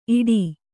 ♪ iḍī